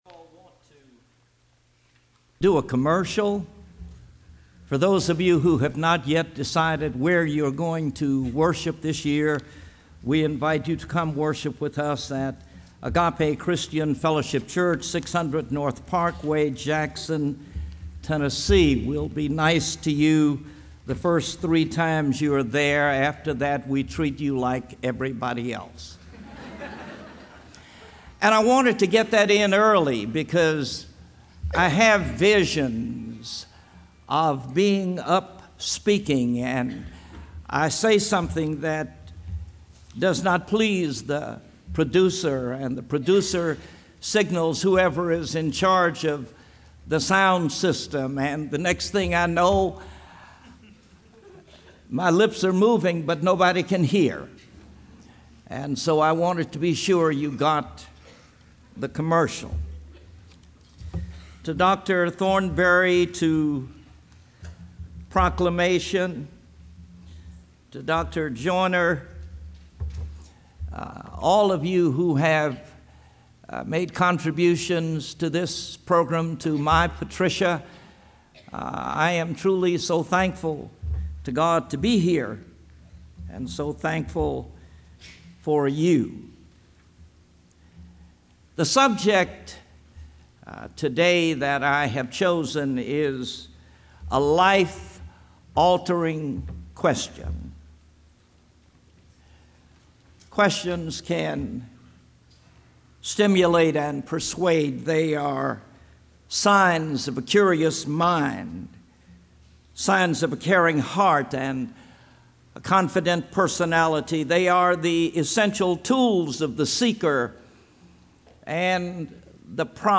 Address: "A Life-Altering Question"